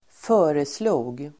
Uttal: [²f'ö:reslo:g]